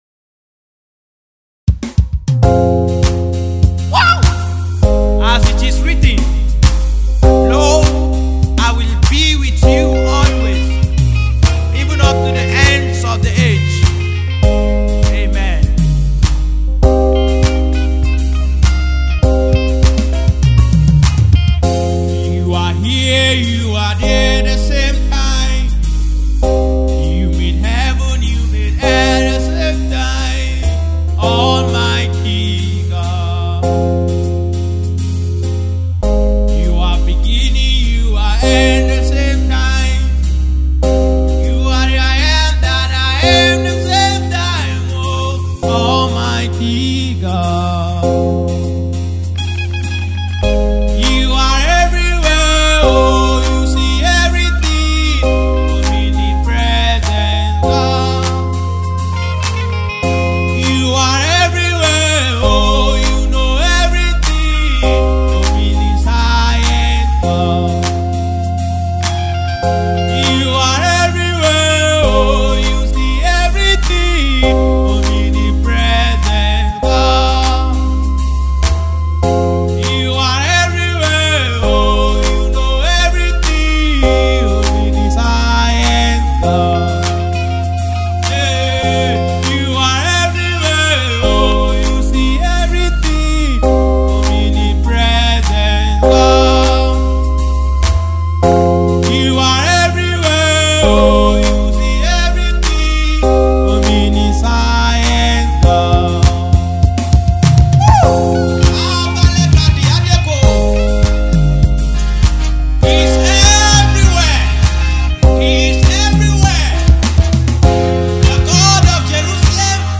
Nigerian Kano based gospel music minister